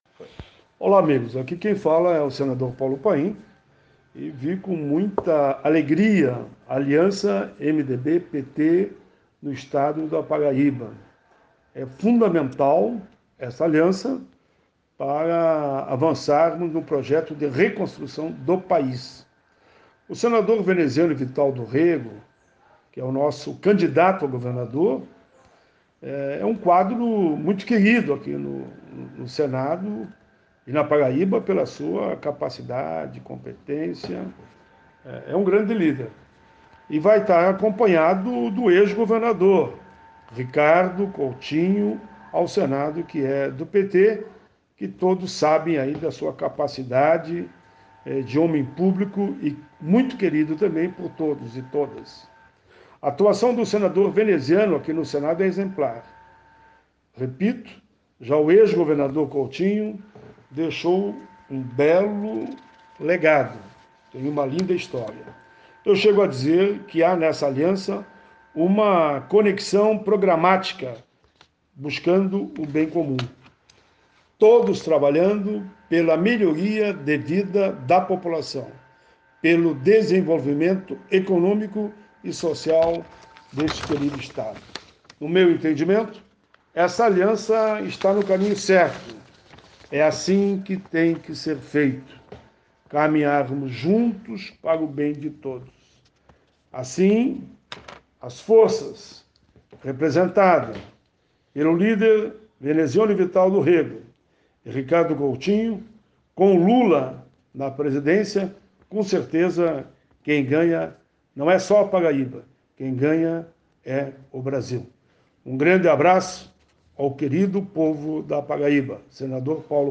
Em entrevista à imprensa paraibana nesta quarta-feira (09.03.2022) Paulo Paim disse que vê “com muita alegria” aliança MDB/PT no estado da Paraíba.